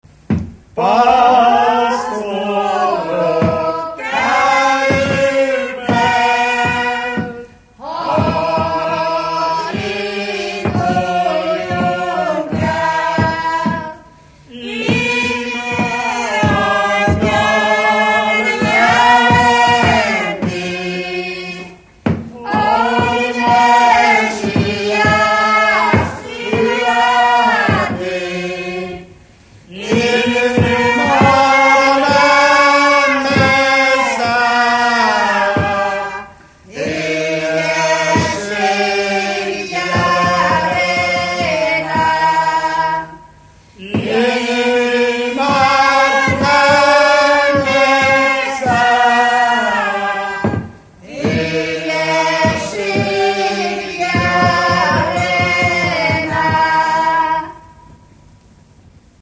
Moldva és Bukovina - Bukovina - Andrásfalva
Műfaj: Betlehemes
Stílus: 8. Újszerű kisambitusú dallamok